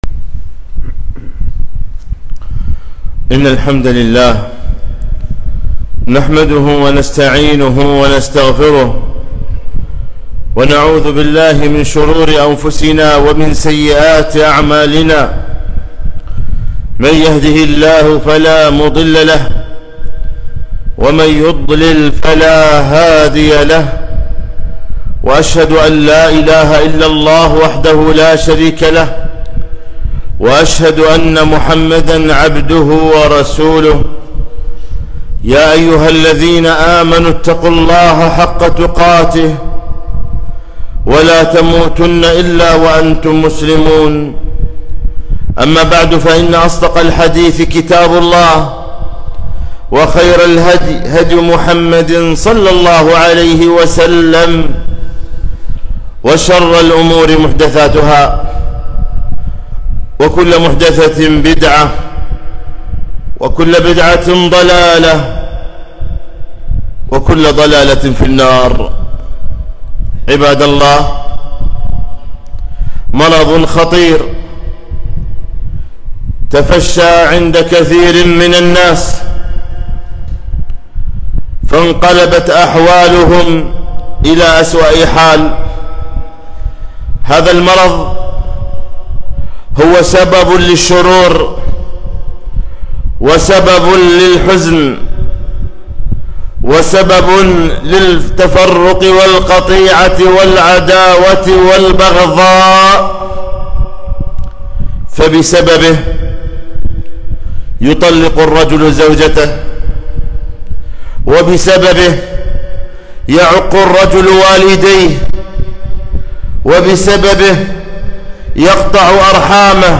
خطبة - ( لا تغضب)